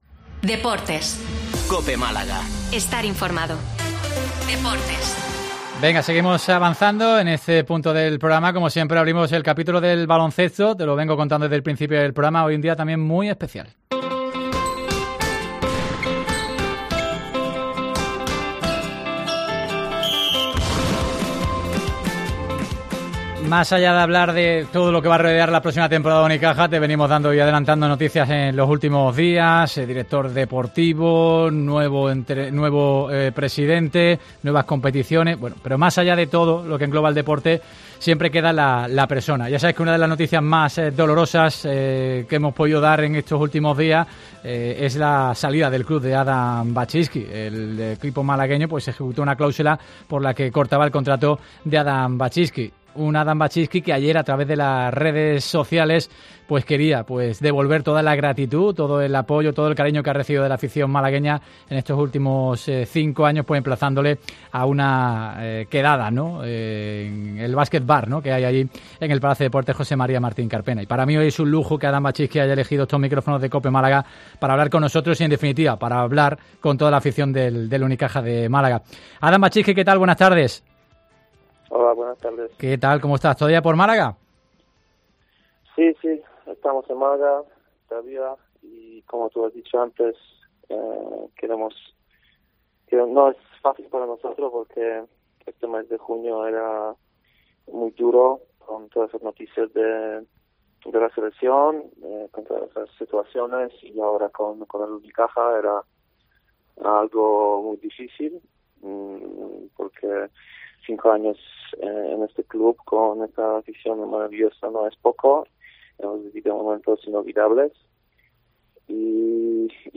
EMOCIONADO
Wazcynski ha roto su silencio en COPE Málaga y en una emotiva entrevista ha hablado a corazón abierto de sus sentimientos y todo las sensaciones que están viviendo: " Ha sido un mes muy duro y difícil con la selección y Unicaja .